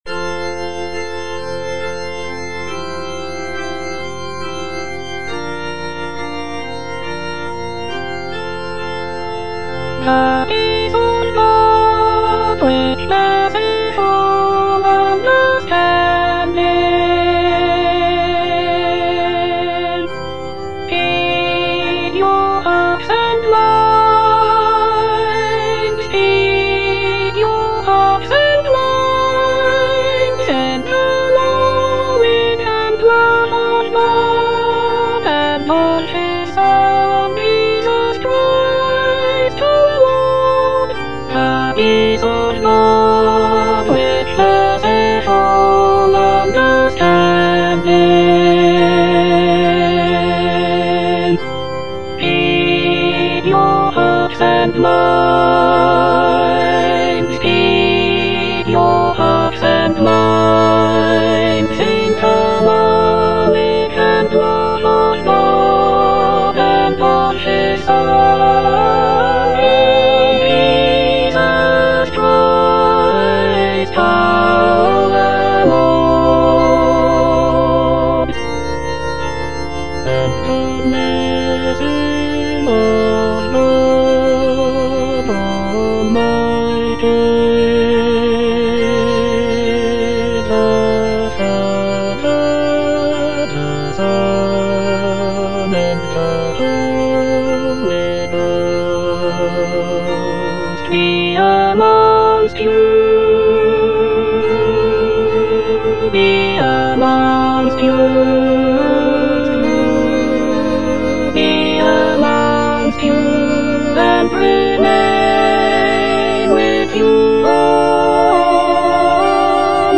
Alto (Emphasised voice and other voices)
choral anthem
lush harmonies and melodic beauty